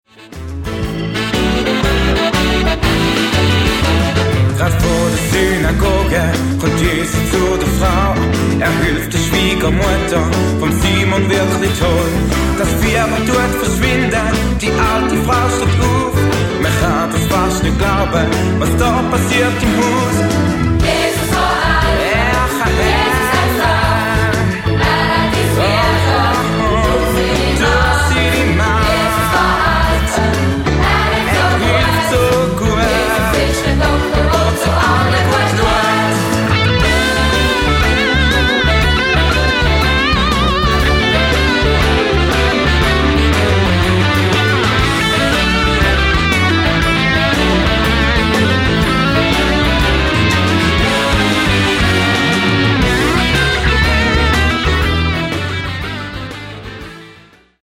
Musical-CD mit Download-Code